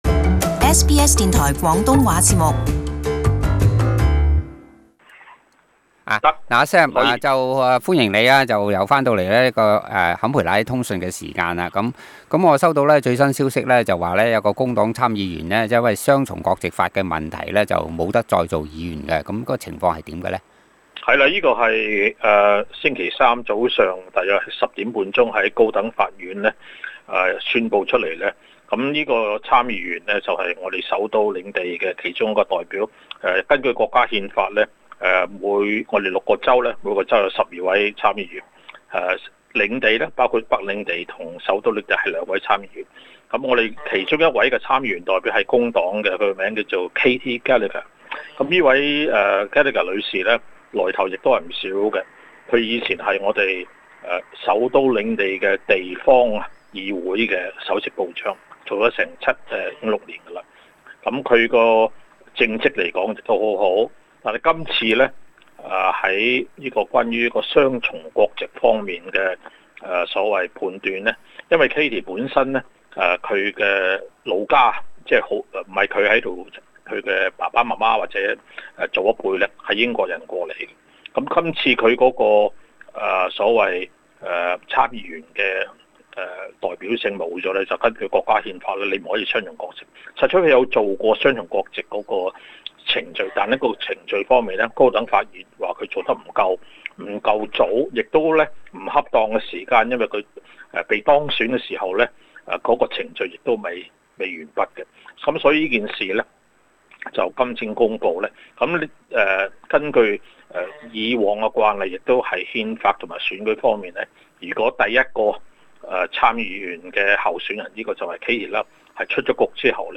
報導